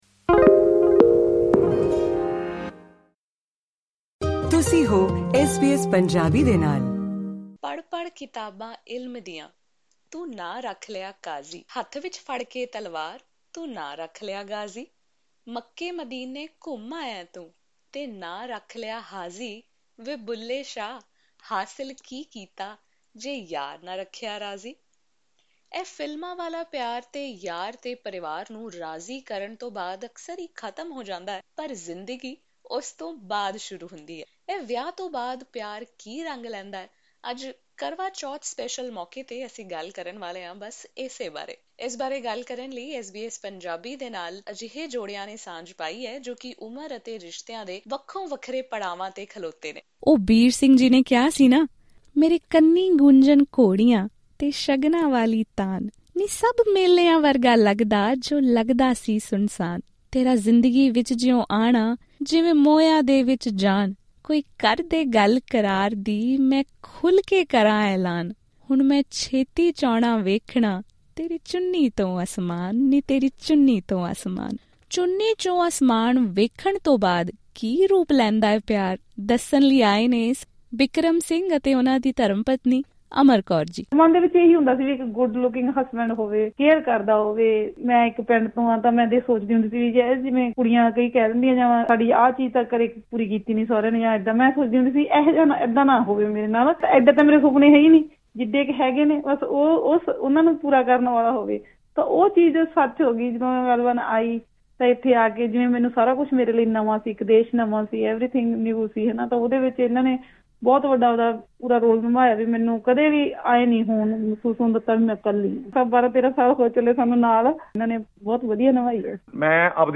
ਜ਼ਿਆਦਾਤਰ ਫ਼ਿਲਮਾਂ ਉਦੋਂ ਖ਼ਤਮ ਹੋ ਜਾਂਦੀਆਂ ਹਨ ਜਦੋਂ ਹੀਰੋ ਅਤੇ ਹੀਰੋਇਨ ਮਿਲਦੇ ਹਨ ਅਤੇ ਇਕੱਠੇ ਰਹਿਣ ਦਾ ਫੈਸਲਾ ਕਰਦੇ ਹਨ ਪਰ ਉਸ ਤੋਂ ਬਾਅਦ ਕੀ ਹੁੰਦਾ ਹੈ? ਐਸ ਬੀ ਐਸ ਪੰਜਾਬੀ ਦੇ ਇਸ ਕਰਵਾਚੌਥ ਸਪੈਸ਼ਲ ਪੌਡਕਾਸਟ ਵਿੱਚ, ਆਓ ਉਨ੍ਹਾਂ ਜੋੜਿਆਂ ਨੂੰ ਸੁਣੀਏ ਜੋ ਜ਼ਿੰਦਗੀ ਦੇ ਵੱਖ-ਵੱਖ ਪੜਾਵਾਂ ਉੱਤੇ ਹਨ, ਤੇ ਇਹ ਜਾਣਦੇ ਹਾਂ ਕਿ ਵਿਆਹ ਤੋਂ ਬਾਅਦ ਪਿਆਰ ਕੀ ਰੂਪ ਲੈਂਦਾ ਹੈ।